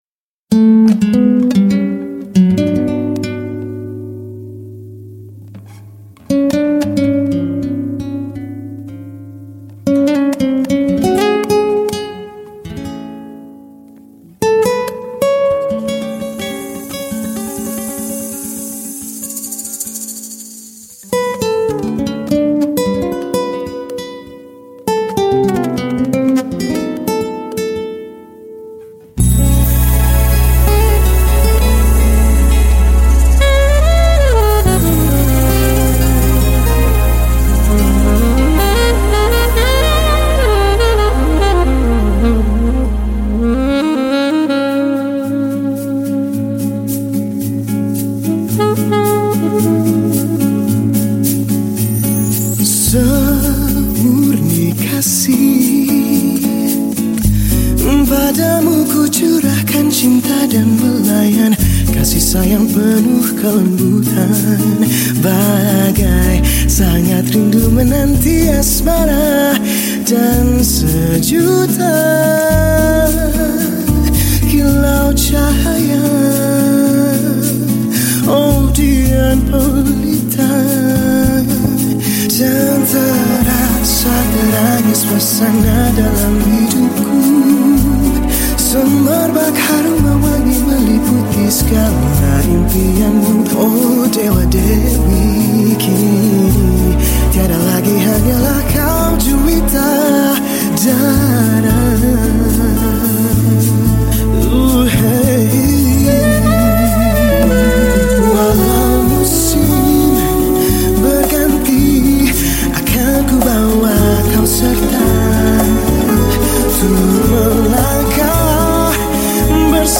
Genre Musik                                 : Musik Pop
Instrumen                                      : Vokal